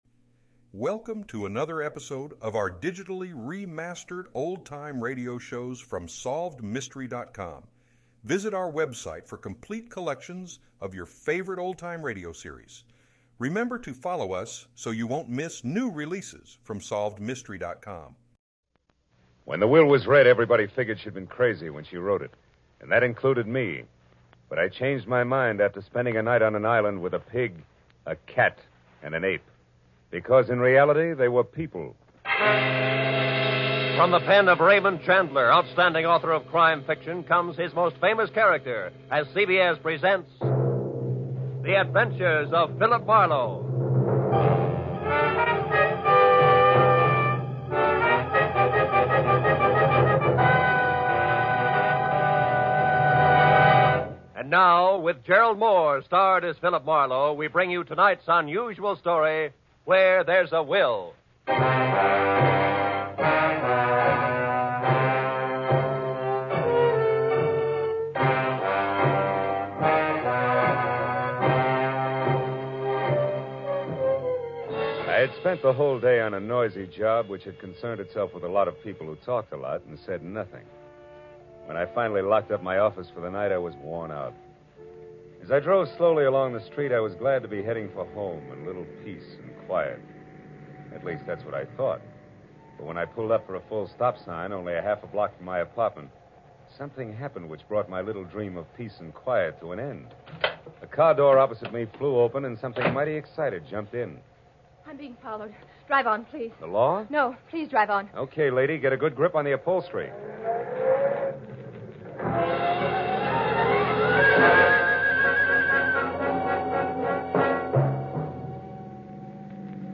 Gerald Mohr starred as Marlowe, and the show was known for its gritty and realistic portrayal of crime and the criminal underworld.
In addition to Mohr's excellent portrayal of Marlowe, the show also featured a talented supporting cast of actors and actresses, including Howard McNear and Parley Baer.